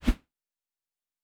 Whoosh 12_8.wav